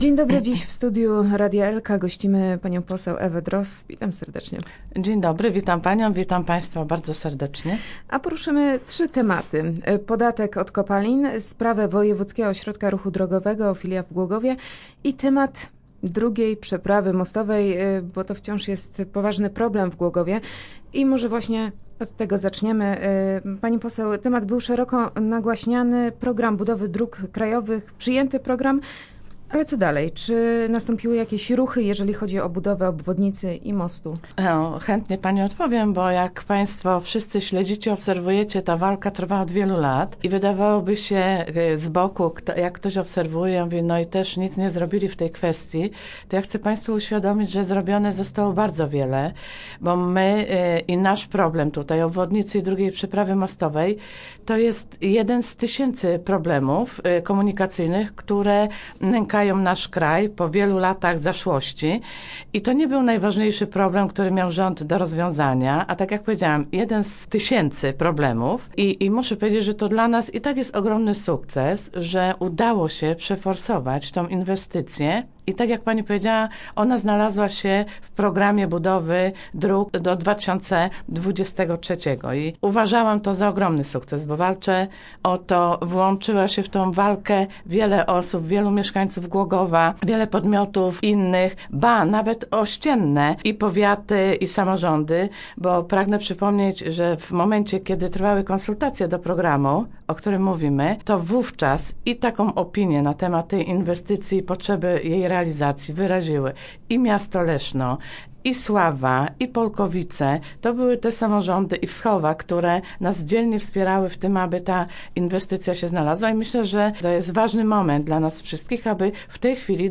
0223_ewadrozd_calosc.mp3